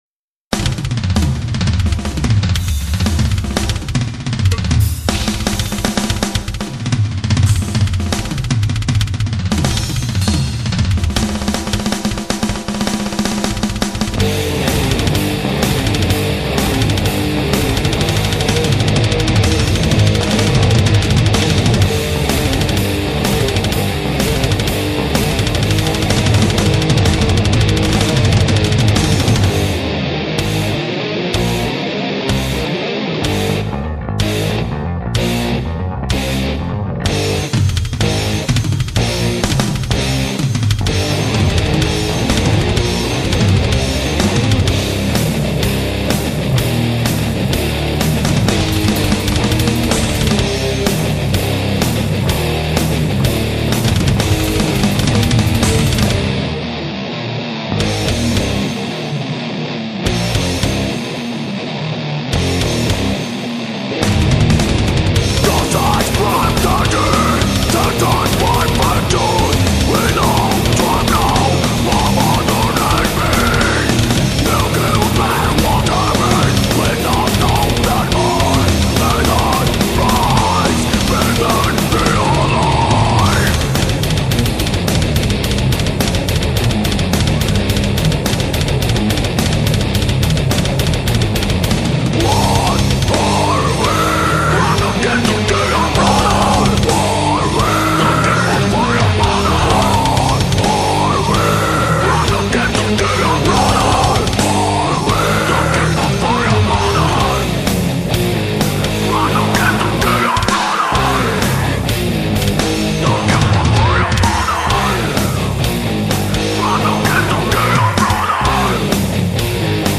EstiloThrash Metal